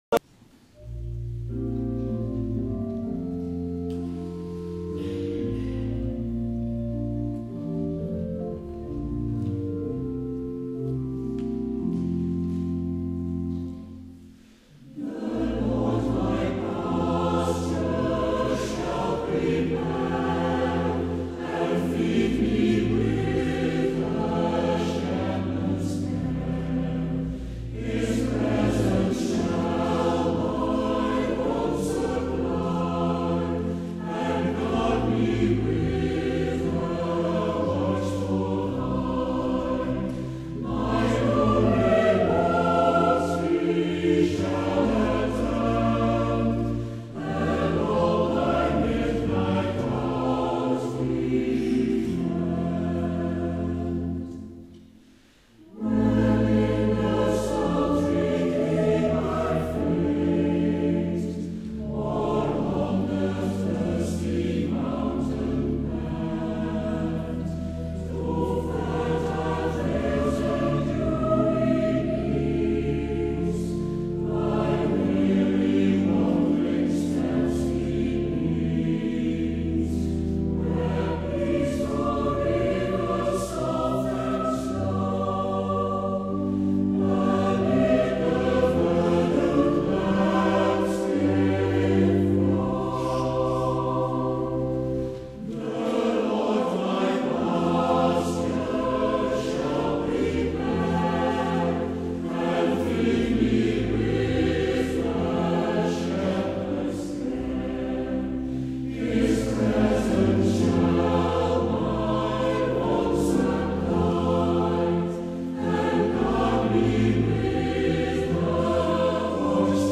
Luisteren naar Arpeggio – Kamerkoor Arpeggio